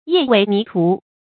曳尾泥涂 注音： ㄧㄜˋ ㄨㄟˇ ㄋㄧˊ ㄊㄨˊ 讀音讀法： 意思解釋： ①同「曳尾涂中」。